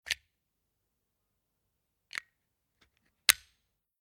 "Sixtant" SM 31 electric shaver
Remove and replace the protective cap
0081_Schutzkappe_abheben_und_aufsetzen.mp3